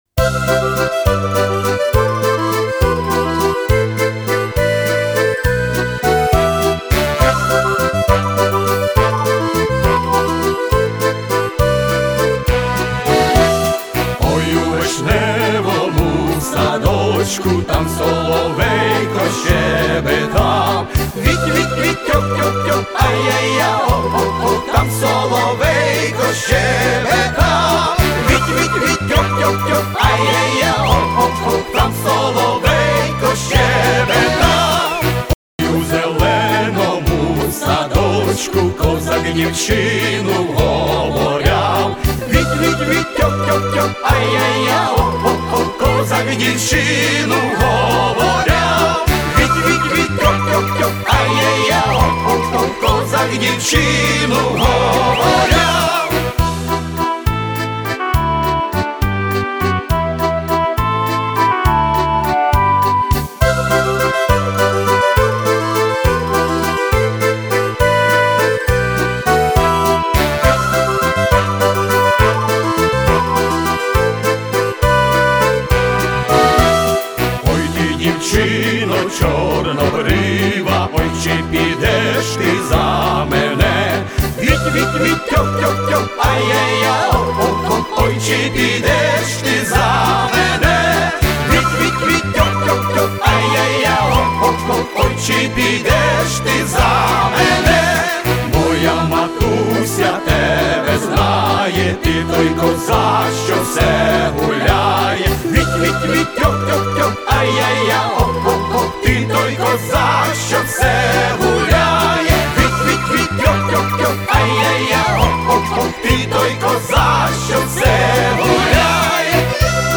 Украинская народная песня